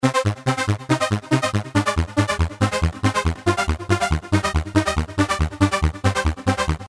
硬式合成器01
Tag: 140 bpm Hardstyle Loops Bass Loops 1.15 MB wav Key : Unknown